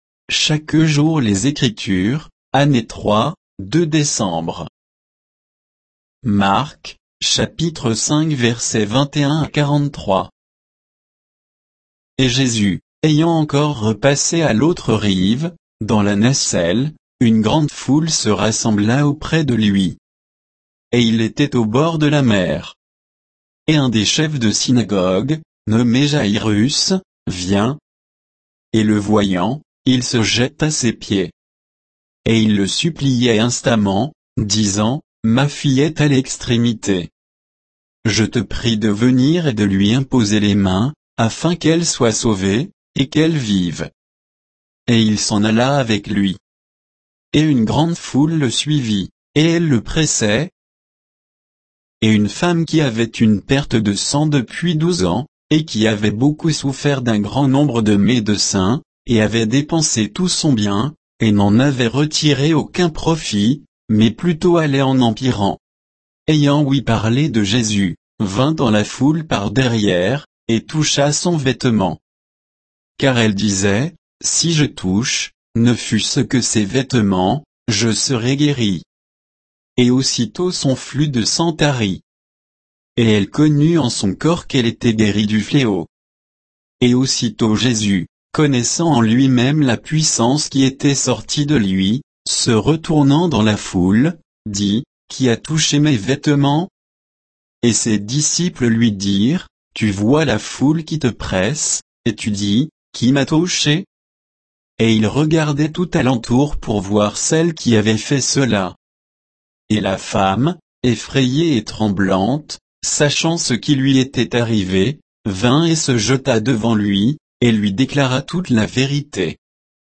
Méditation quoditienne de Chaque jour les Écritures sur Marc 5